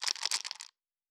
Dice Shake 9.wav